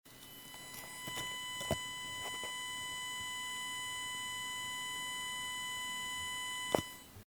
The problem I have is that when I open Cubase 5 I get this ground loop in any of the 4 headphone output channels of my Behringer HA4700.
My PC: Motherboard: ASRock P5B-DE RAM: 8 GB CPU: Intel Core2 quad q9450 HDD: 2x WD with 7200 RPM P.S. The ground loop/buzz/hum doesn't get recorded.
Attachments ground loop - recorded with my android phone.mp3 ground loop - recorded with my android phone.mp3 115 KB · Views: 94